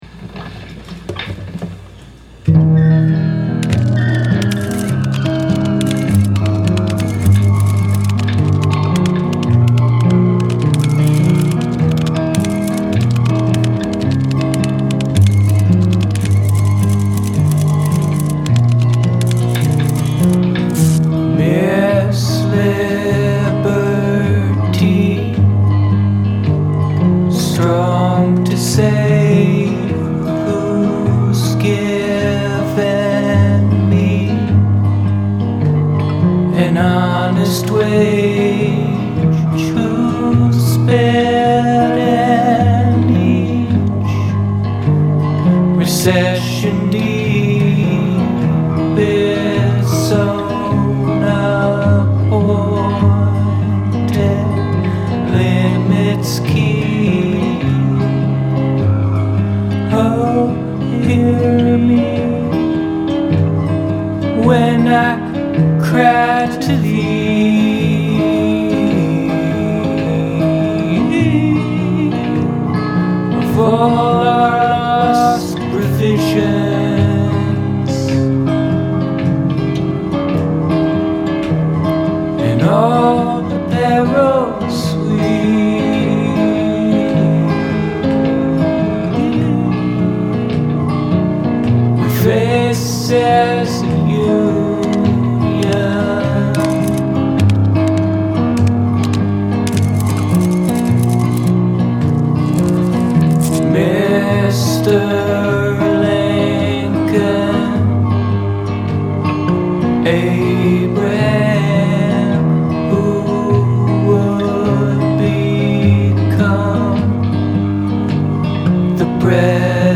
verse (beginning): C, Am, Em, G; verse (end): Am, C7, F, G7